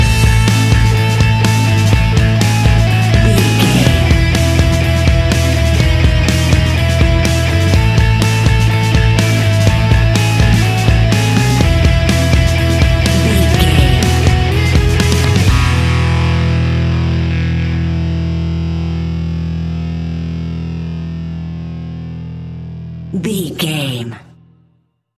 Uplifting
Ionian/Major
guitars
hard rock
distortion
rock guitars
Rock Bass
Rock Drums
heavy drums
distorted guitars
hammond organ